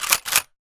gun_shotgun_cock_04.wav